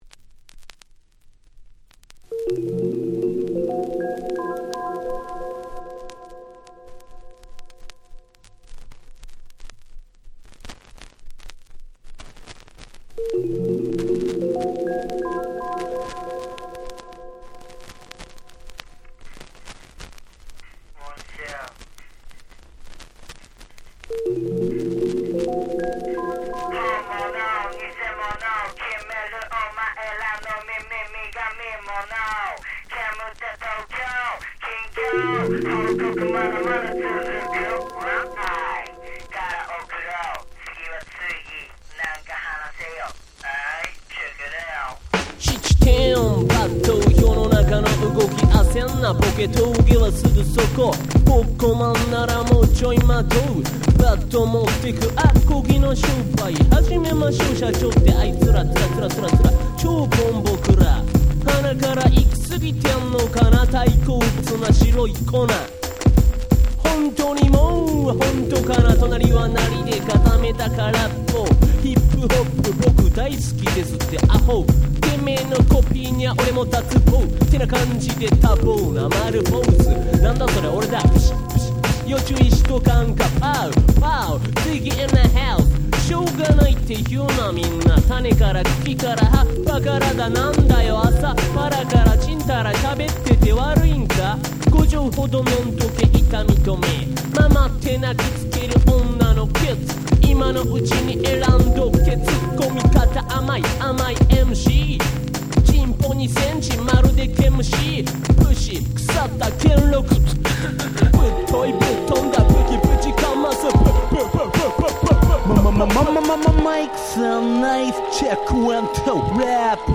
94' Japanese Hip Hop Super Classics !!
説明不要の90's 日本語ラップクラシックスです。